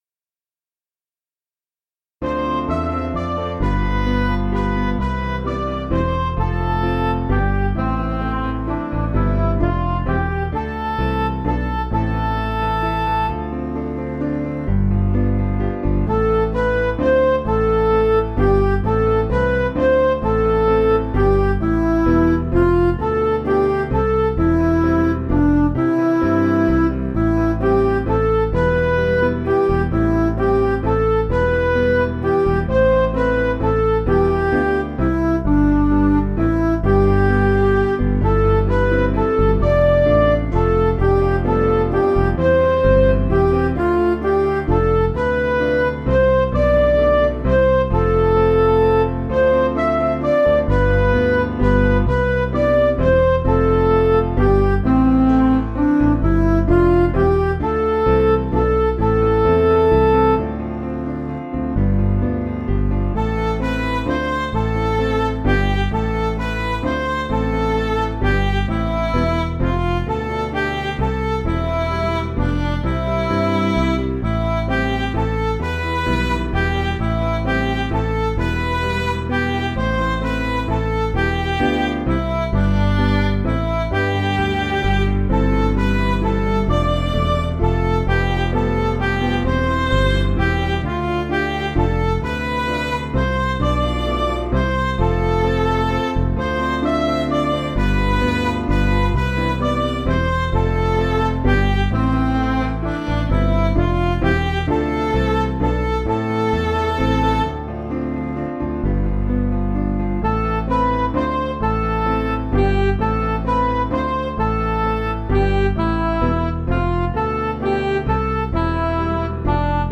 Piano & Instrumental
(CM)   4/Am